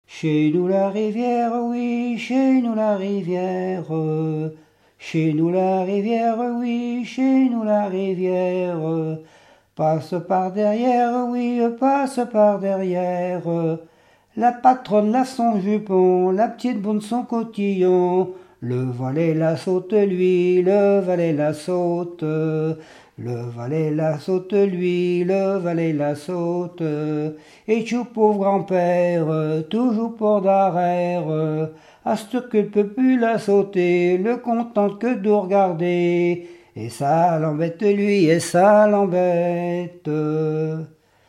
Genre énumérative
Pièce musicale inédite